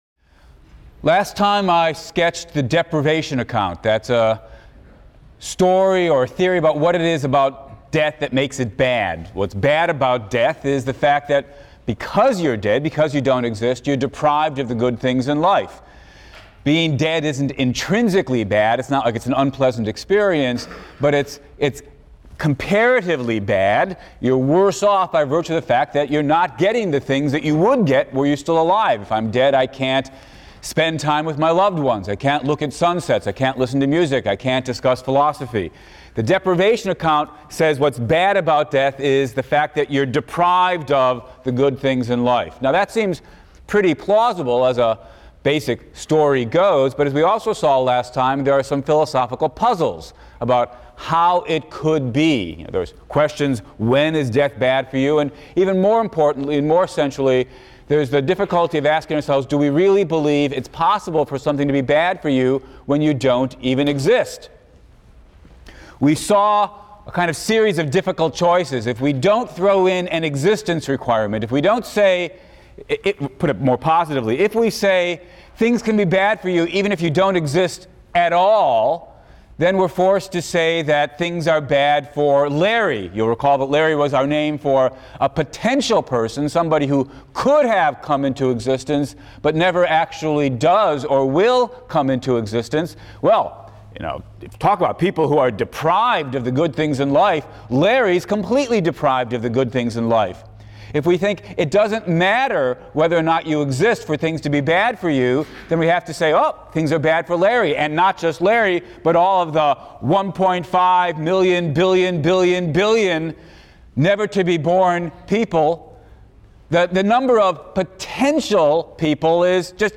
PHIL 176 - Lecture 18 - The Badness of Death, Part III; Immortality, Part I | Open Yale Courses